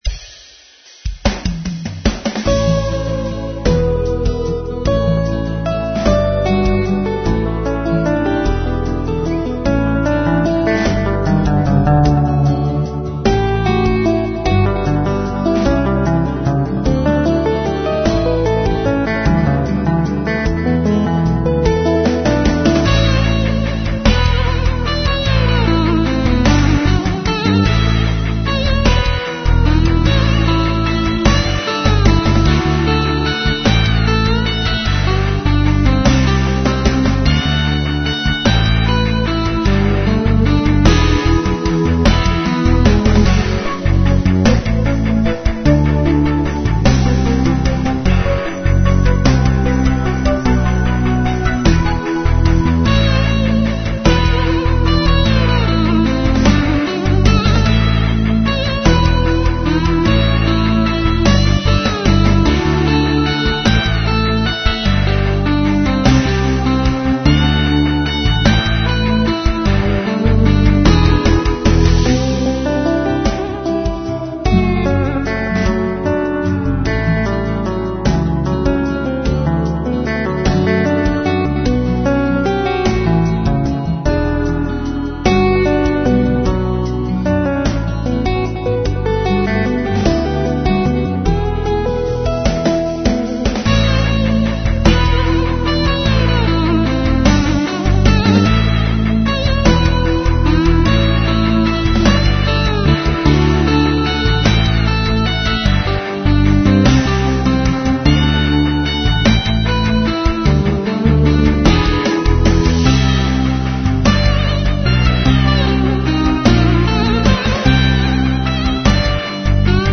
Rock Waltz Ballad with Synth-Guitar Lead